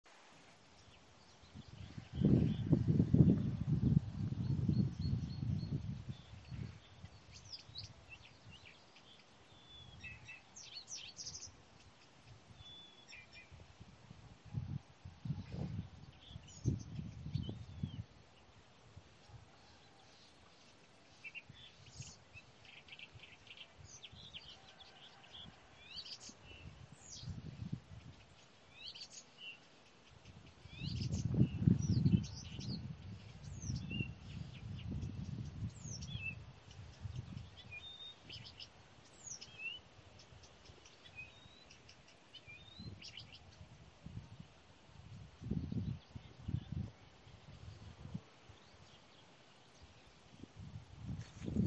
садовая камышевка, Acrocephalus dumetorum
Administratīvā teritorijaGulbenes novads
СтатусПоёт
Примечания/uzturās zemu, avenājos. dzied. izdevās izvilināt ar purva ķauķa dziesmas ierakstu - izpildīja saucienu